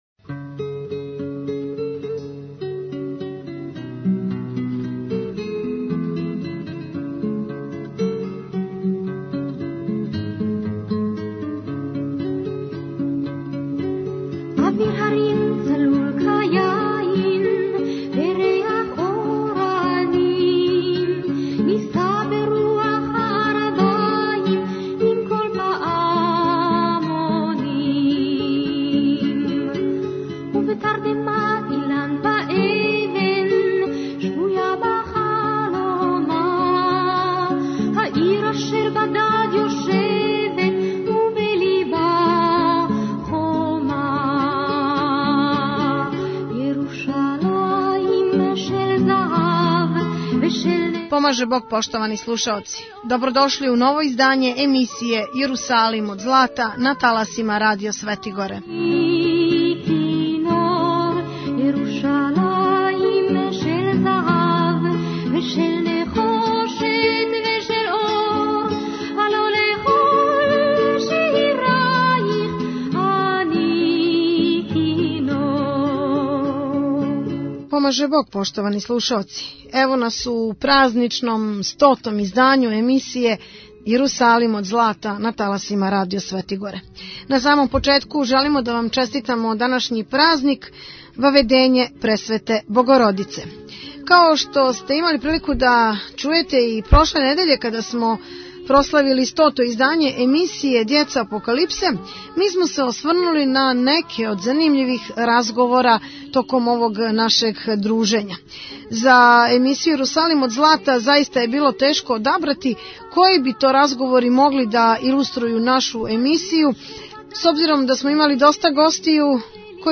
Download the file . 90:34 минута (15.55 МБ) Ове недеље слушате 100 – ту емисију Јерусалим од злата, кроз коју ћемо се осврнути на неке од разговора са драгим гостима, сарадницима и чути разнолике теме којима смо се бавили у предходних 99 емисија.